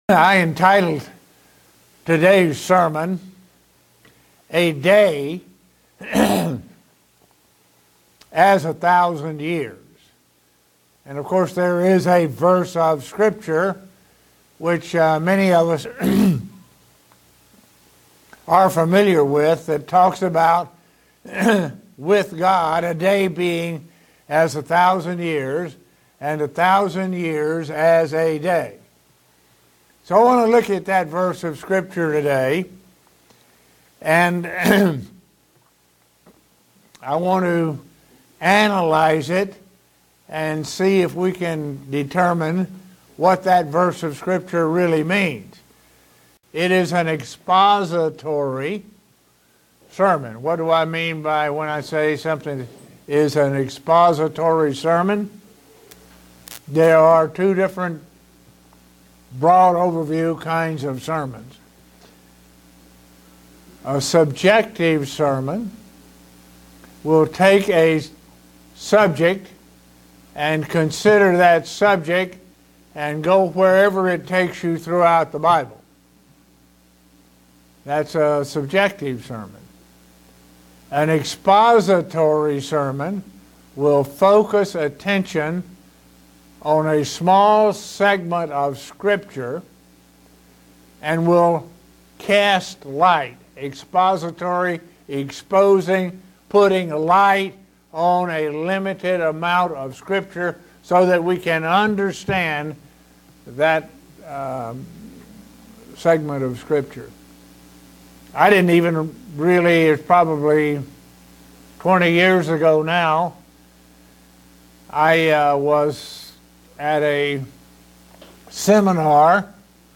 Given in Buffalo, NY
Print God has dedicated seven thousand years to fulfill His purpose. sermon Studying the bible?